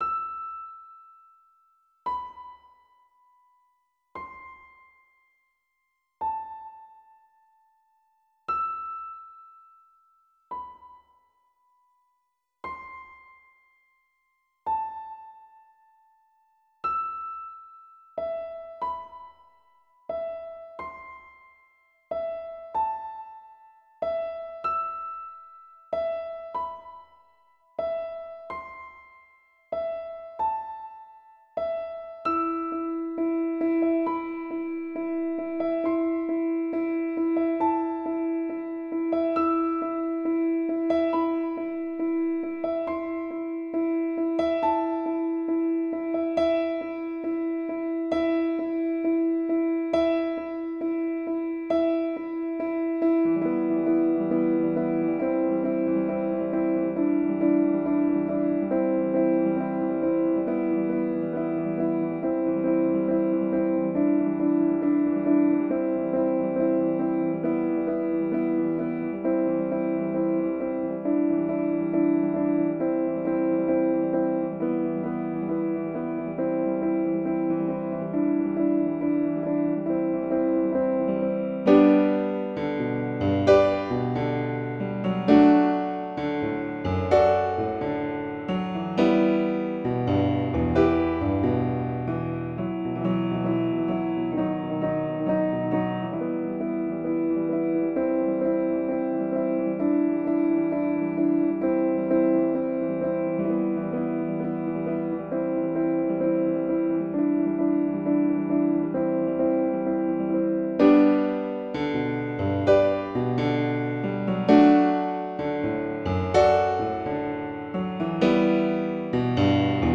Version piano seul